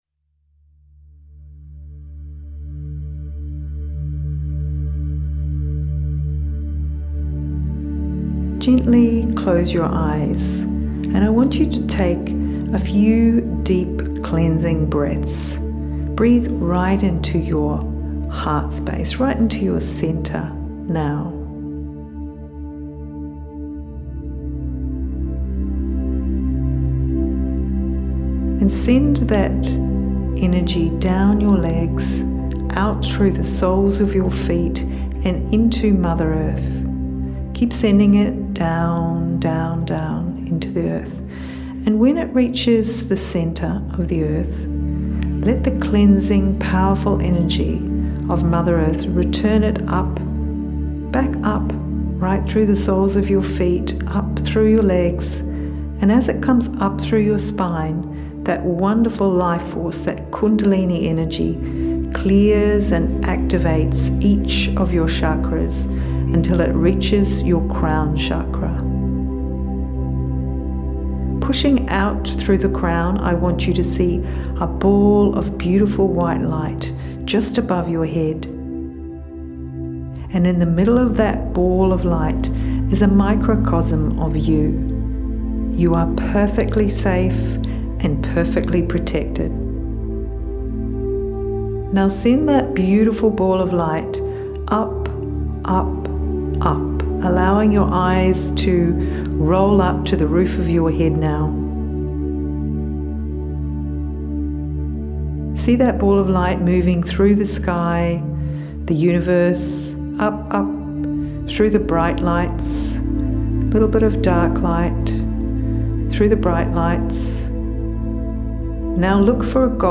05:20 Download MP3 So here it is - this is my beautiful Theta healing meditation to be enjoyed daily or additionally as required. It will not only download you with unconditional love from creator, it will also serve to protect your beautiful sensitive creative energy out in the world.